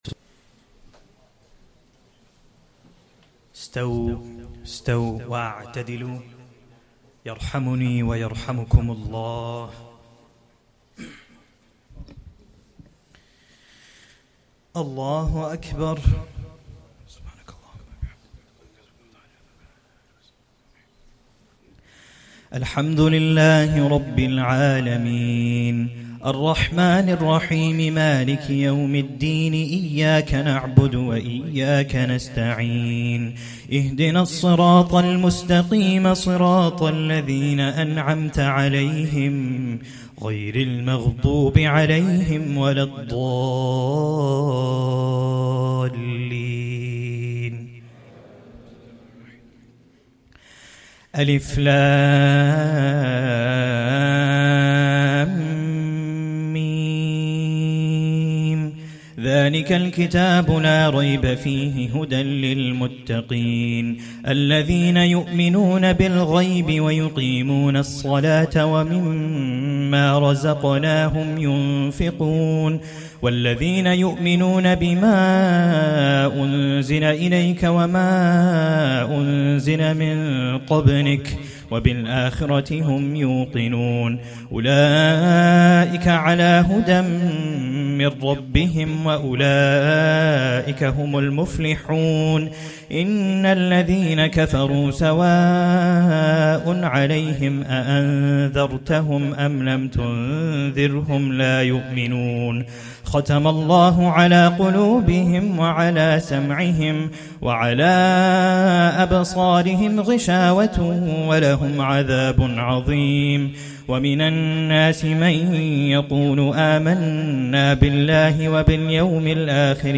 1st Taraweeh Prayer - 1st Ramadan 2024